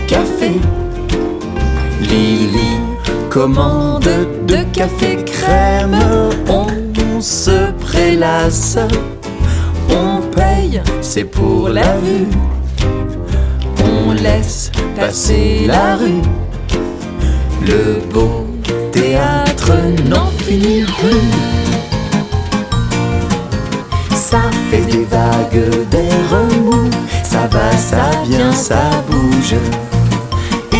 0 => "Chansons pour enfants"